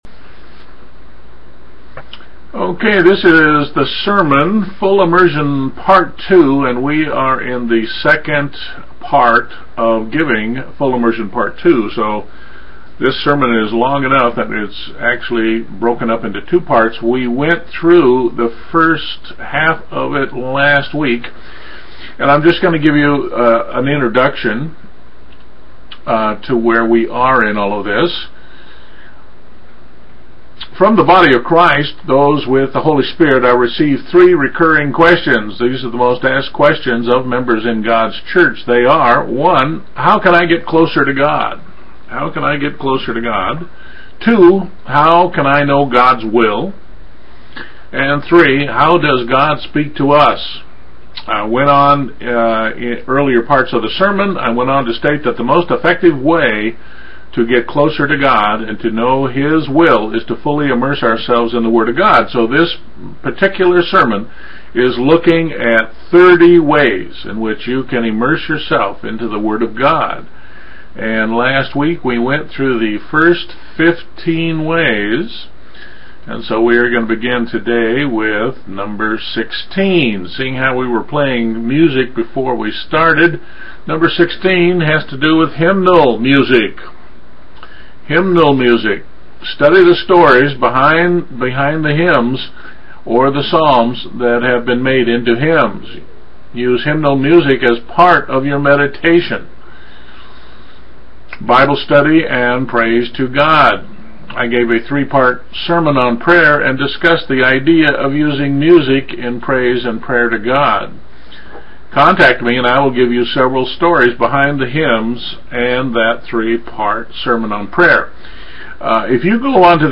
Sermon: Full Immersion Part 1